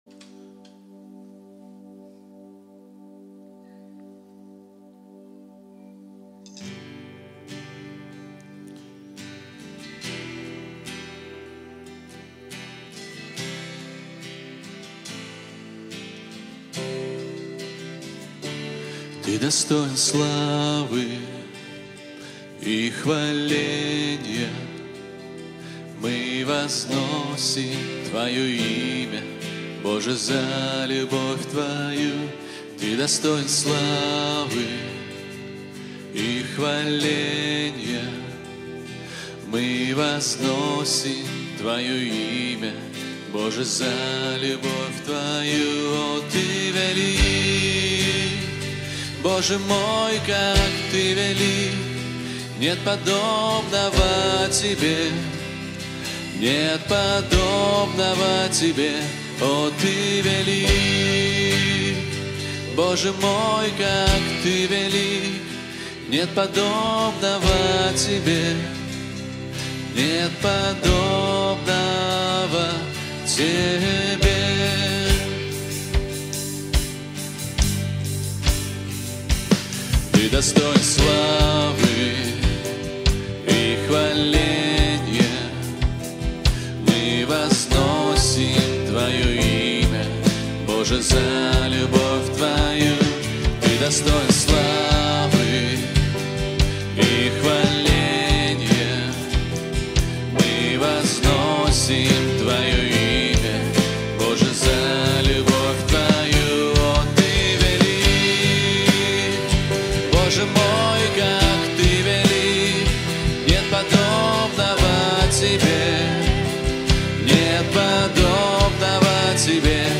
929 просмотров 736 прослушиваний 69 скачиваний BPM: 69